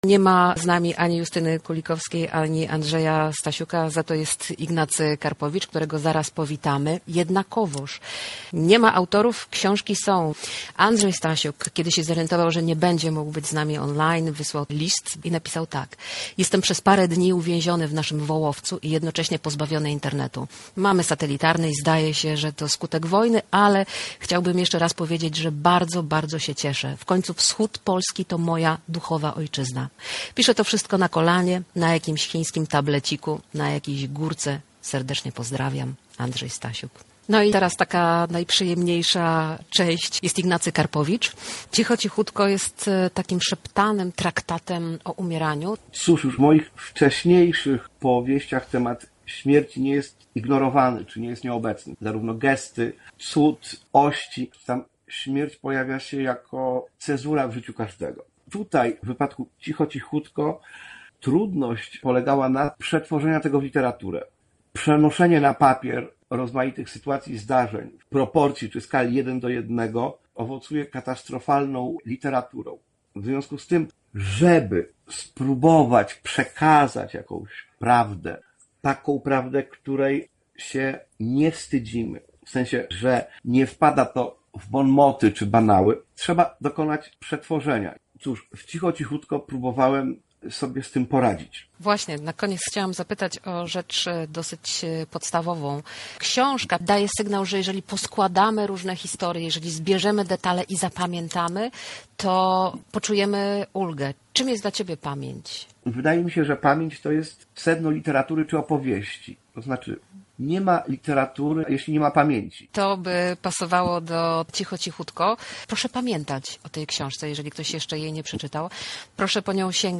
Spotkanie z Ignacym Karpowiczem - relacja